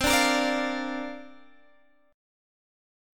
C7sus2sus4 chord